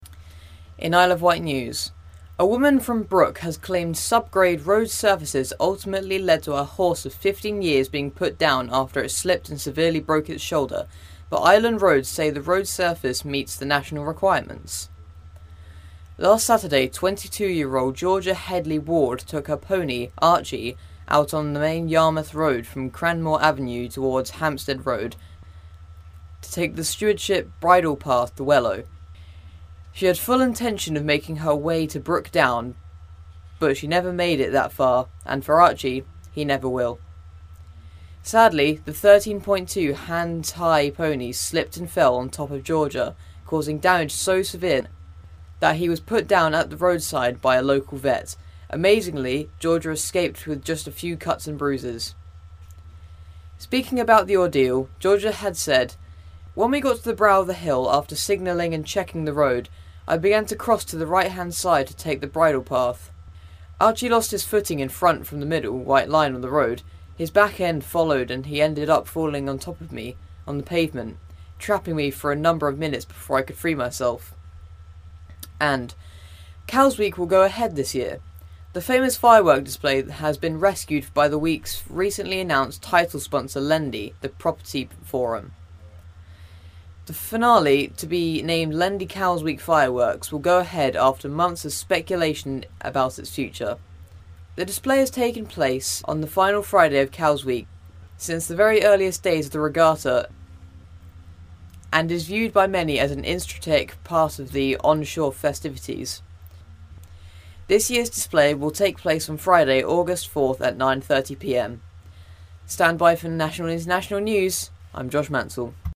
Reads Friday's News